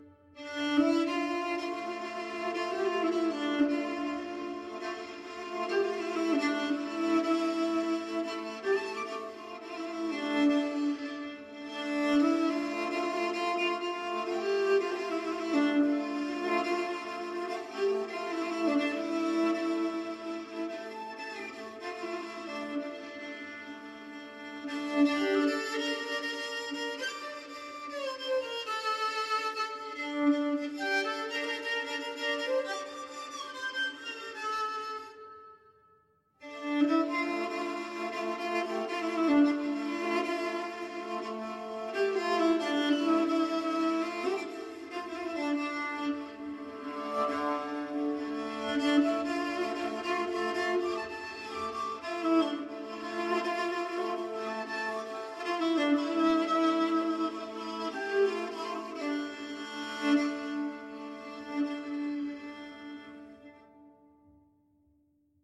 une prière arménienne
J’ai eu la chance de le rencontrer à Romainville.